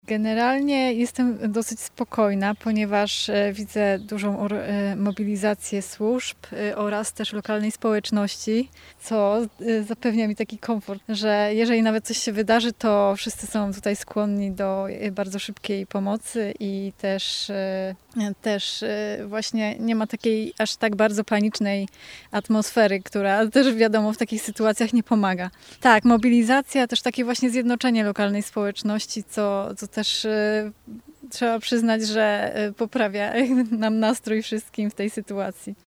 Mieszkańcy podkreślają, że pomimo niepewności i stresu, atmosfera na osiedlu jest bardziej mobilizująca niż paniczna.